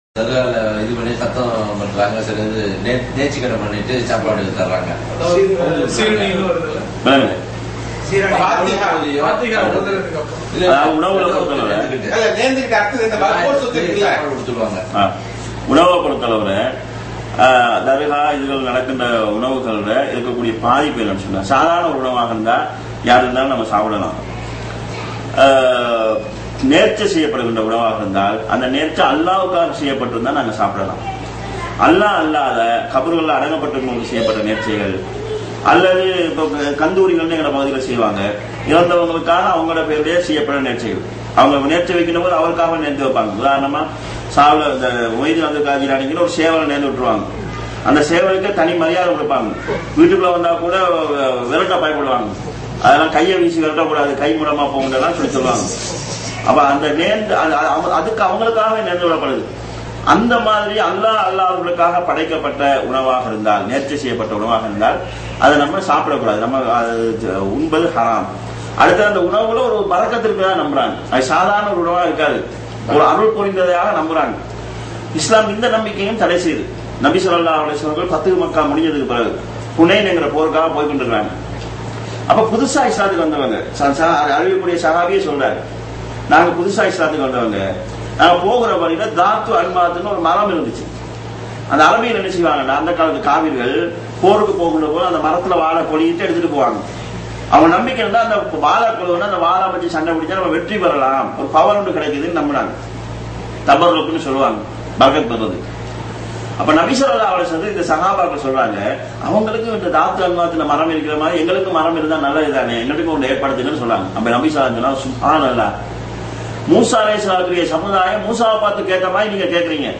நிகழ்ச்சி : சிறப்பு பயான் நிகழ்ச்சி (கேள்வி-பதில் பகுதி)
இடம் : அல்-கப்ஜி, சவூதி அரேபியா